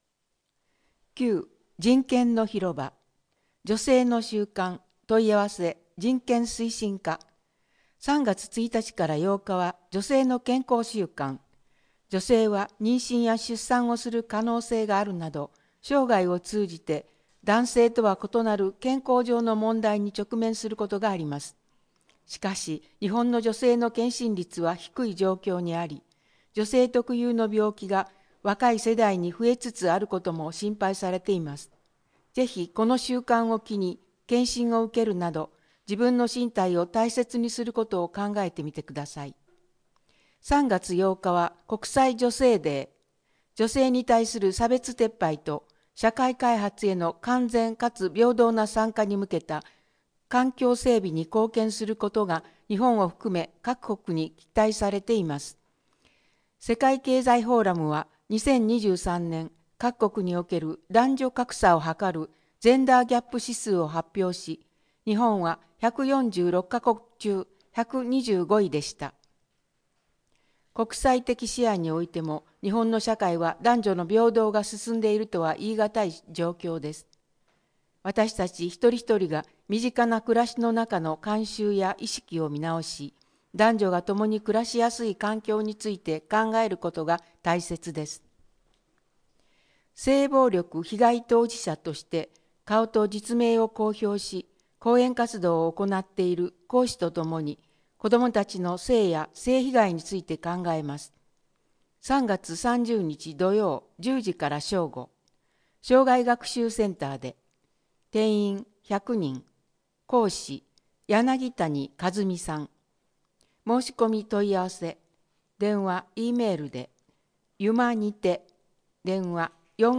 このページでは、視覚障害をお持ちの方のために泉佐野市社会福祉協議会「声のボランティア」のみなさんが朗読した広報の音声ファイルをダウンロードできます。